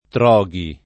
Troghi [ tr 0g i ]